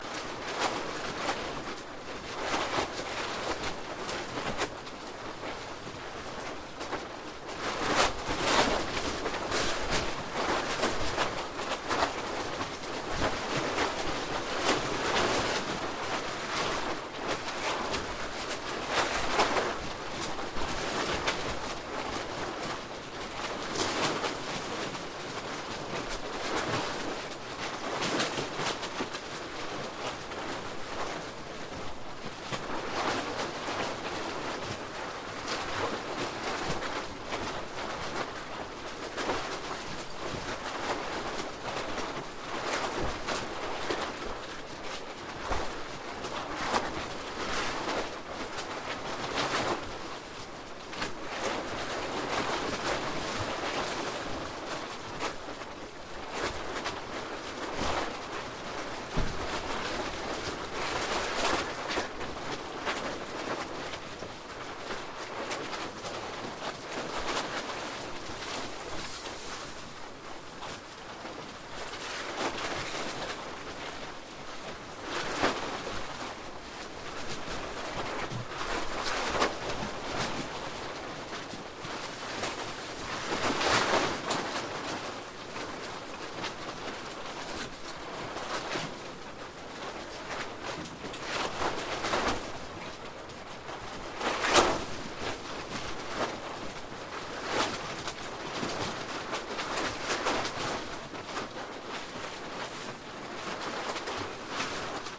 Шум ветра в стоящей палатке